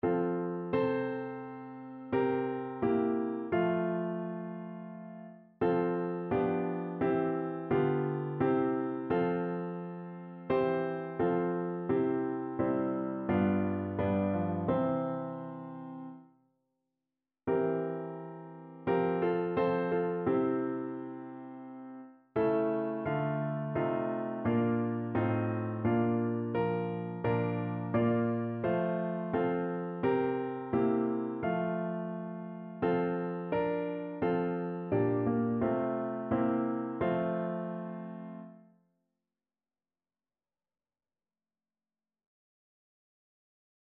Notensatz 1 (4 Stimmen gemischt)
• gemischter Chor mit Akk. [MP3] 686 KB Download